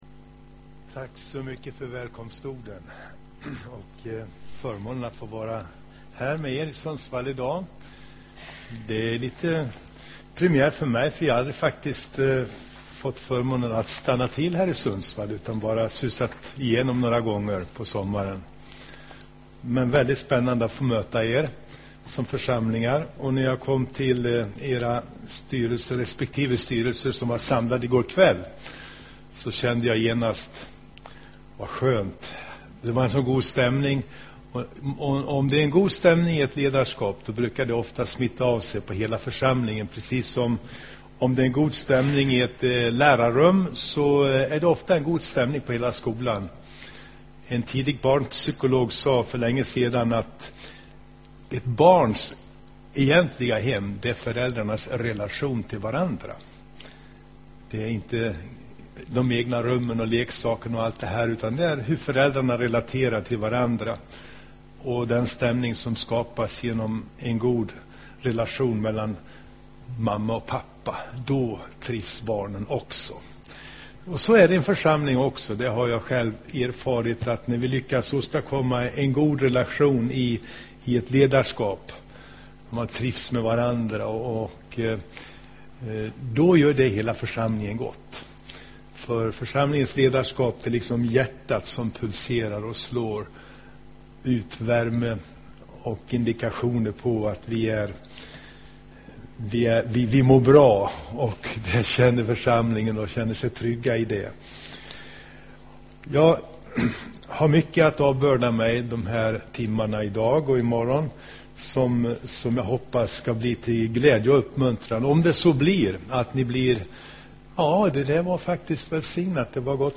Inspelad i Hagakyrkan, Sundsvall 2011-03-19.